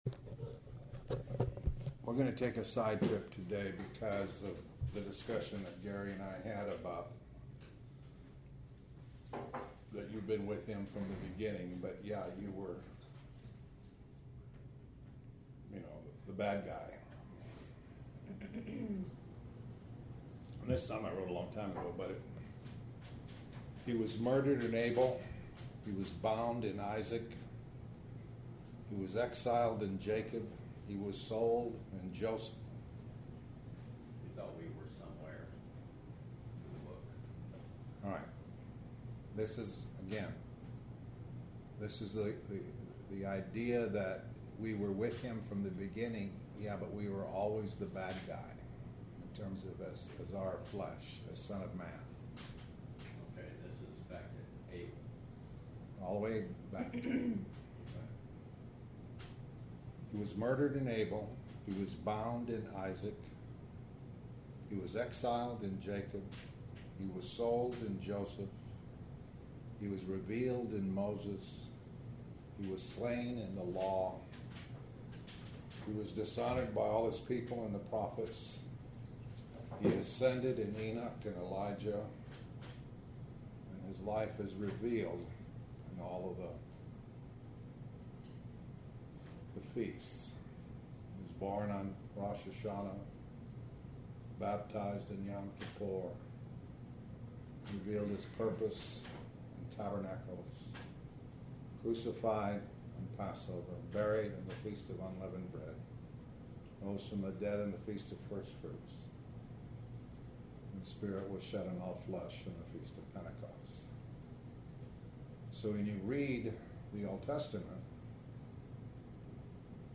Talk about Christ revealed in the OT through Abel, Joseph, Isaac, etc. You were the one who persecuted Christ.